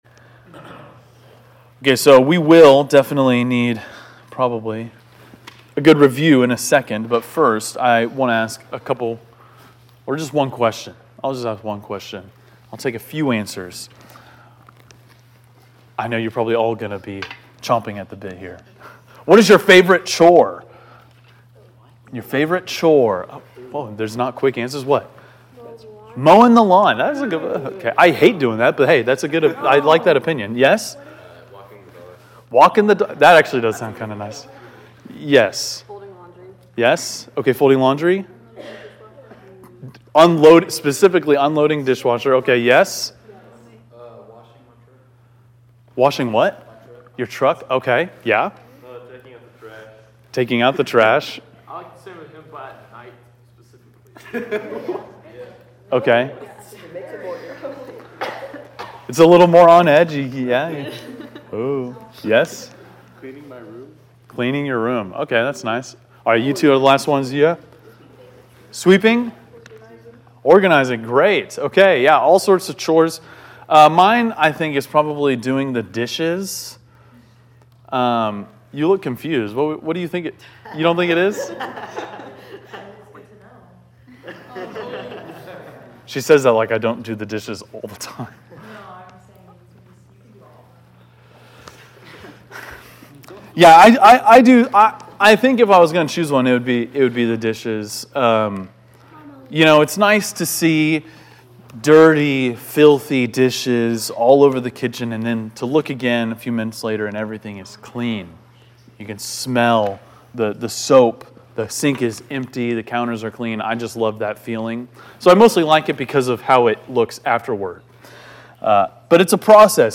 teaches on the topic of sanctification.&nbsp